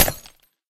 1.21.4 / assets / minecraft / sounds / random / glass3.ogg
glass3.ogg